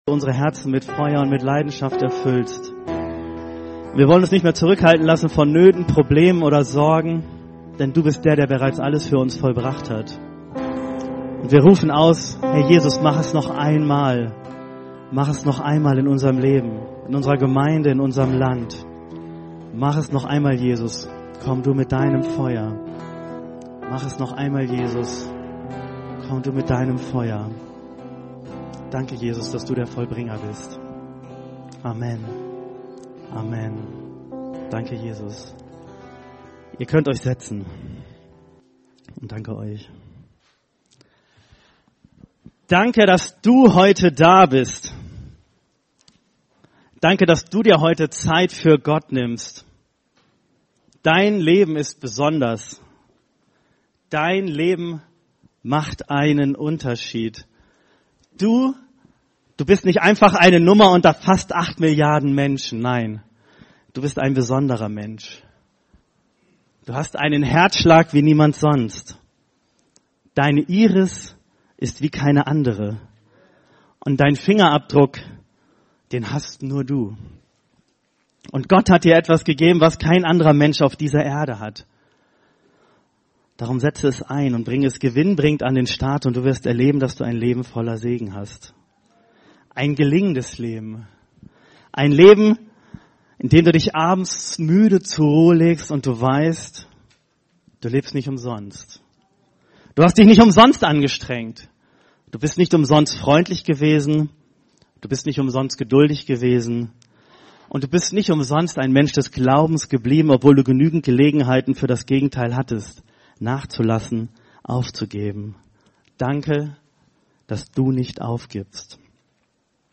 Eine predigt aus der serie "Los geht's."